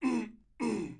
描述：澄清声音
Tag: 语音 短声 让人